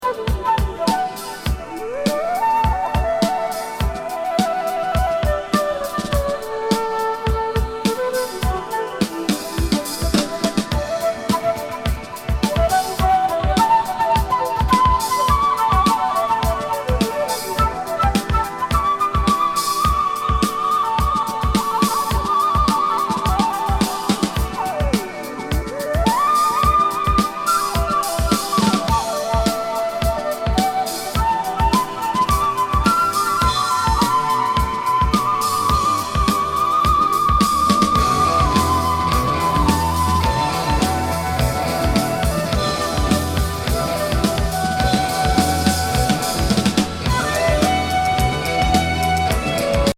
ドイツのエクスペ・フルート奏者の79年作。浮遊感たっぷりのフルート＋
エレクトロニクスのプロッグ・グルーブ